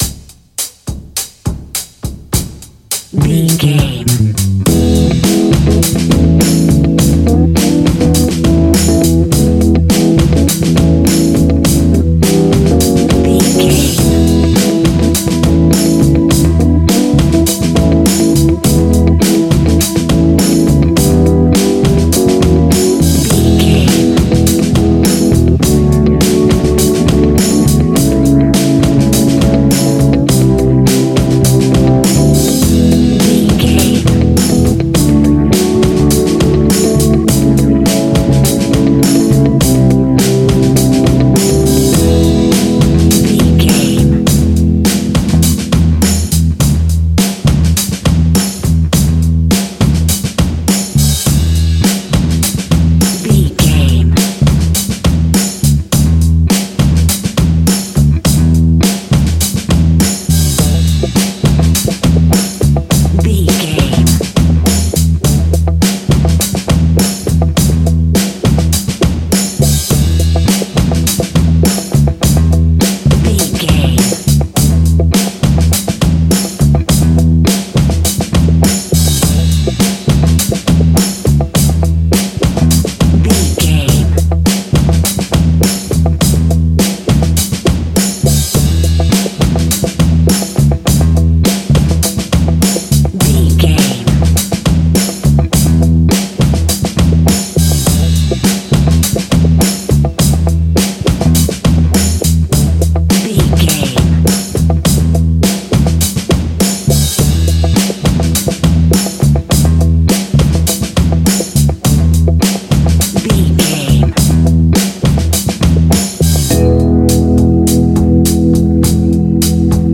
Aeolian/Minor
lively
electric guitar
electric organ
saxophone
percussion